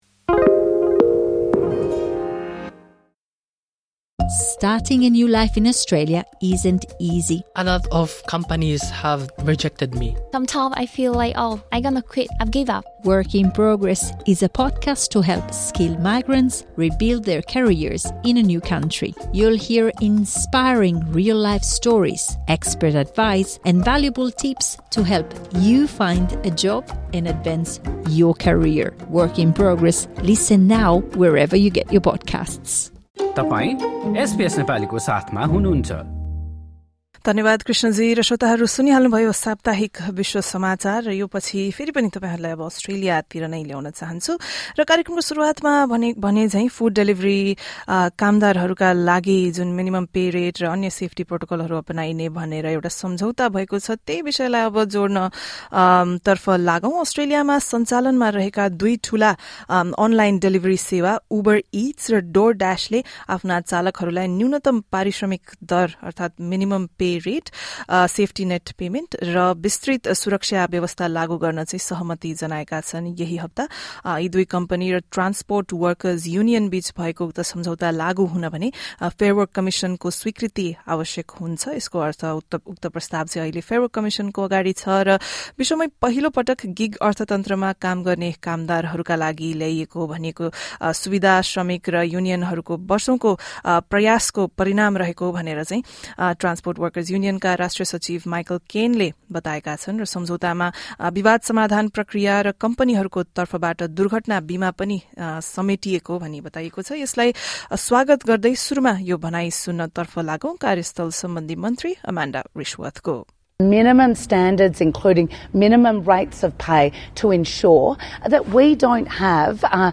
एसबीएस नेपालीले गरेको कुराकानी सहितको रिर्पोट सुन्नुहोस्।